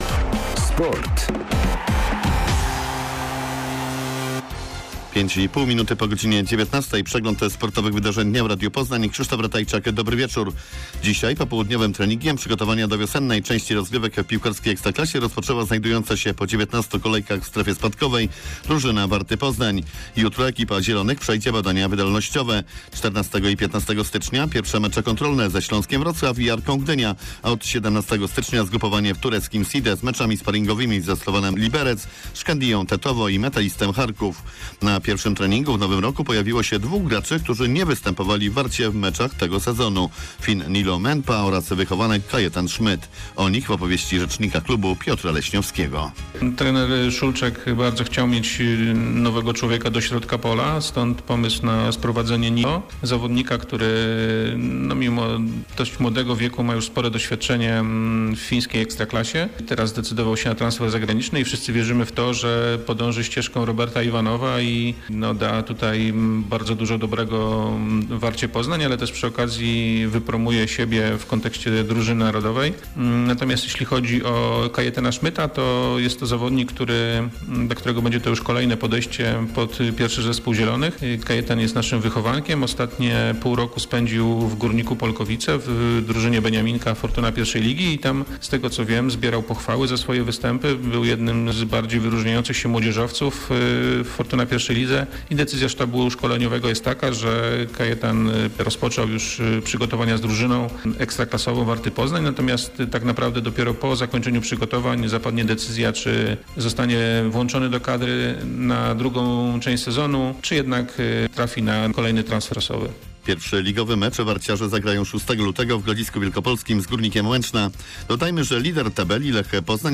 04.01.2022 SERWIS SPORTOWY GODZ. 19:05